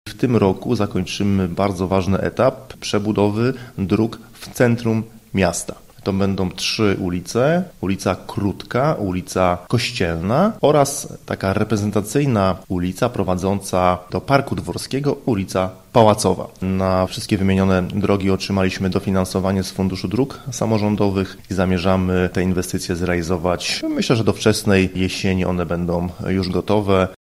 – Plan powstał jeszcze w 2015 roku i sukcesywnie go realizujemy – mówi Paweł Lichtański, burmistrz Iłowej: